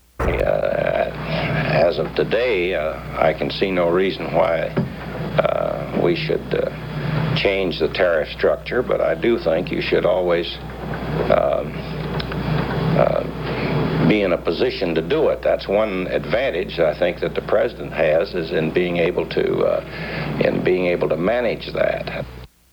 U.S. Secretary of Commerce Rogers Morton discusses the price of oil
Broadcasters CBS Television Network